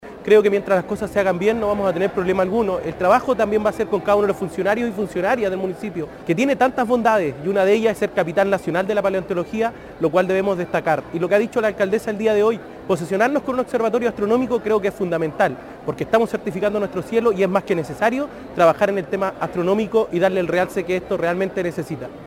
Por su parte, el concejal Edgard Ánjel comentó que es necesario trabajar en las potencialidades de la comuna, como lo es la paleontología y sus cielos oscuros.